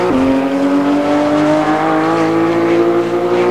Index of /server/sound/vehicles/vcars/porsche911carrera